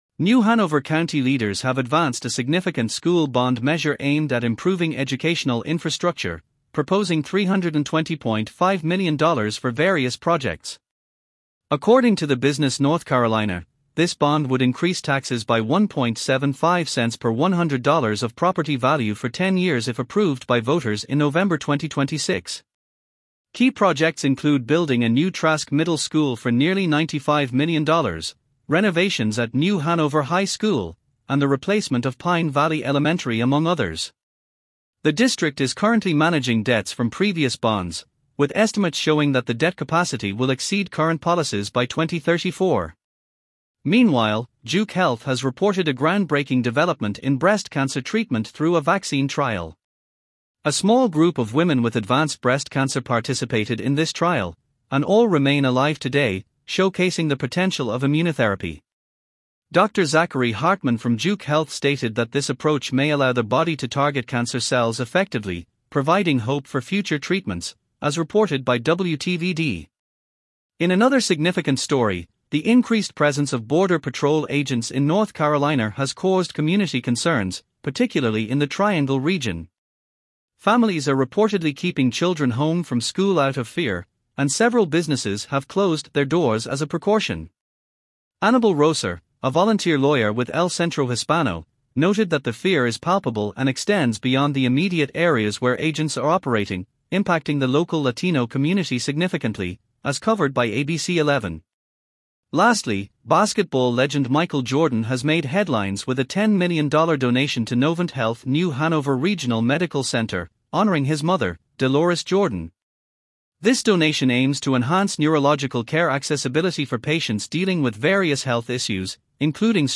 North Carolina News Summary
Regional News